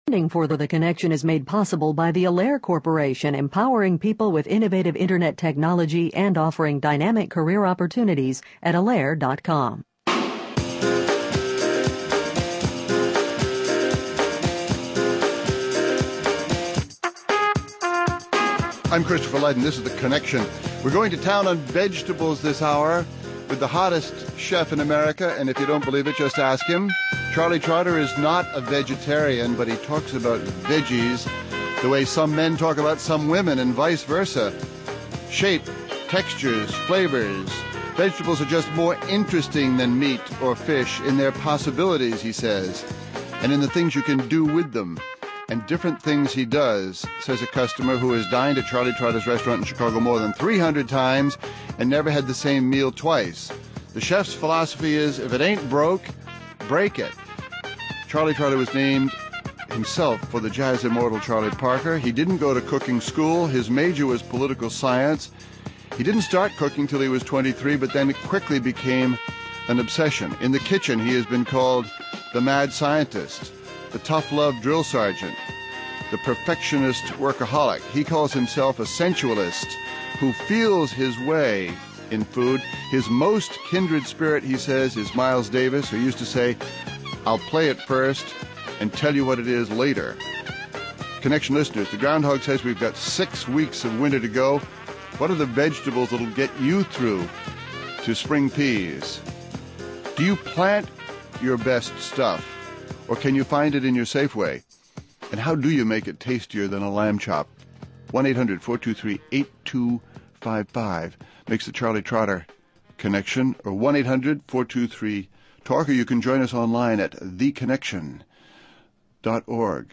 (Hosted by Christopher Lydon)
(Hosted by Christopher Lydon) Guests: Charlie Trotter, chef at Charlie Trotter in Chicago